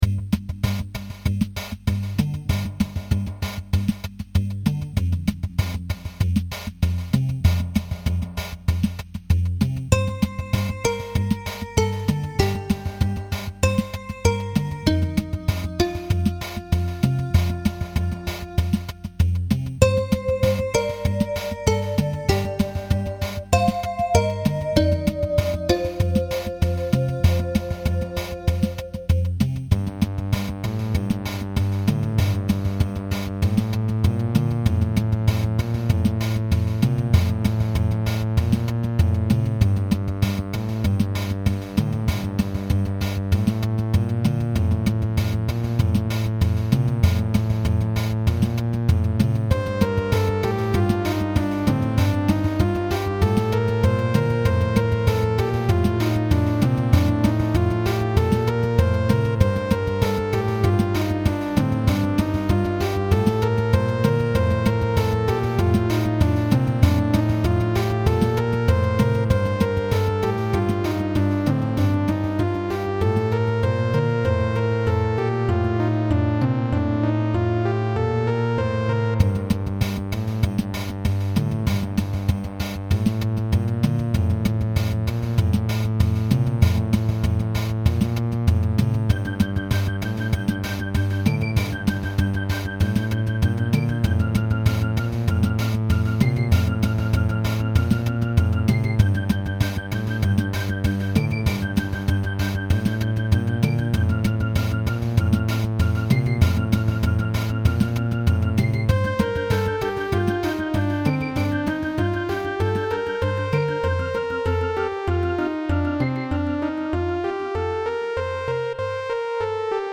Pieza electrónica ambiental